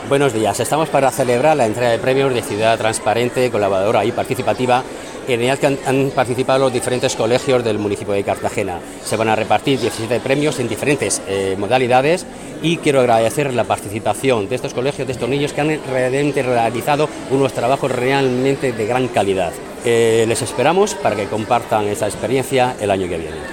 Enlace a Declaraciones del concejal delegado de Transparencia, Diego Lorente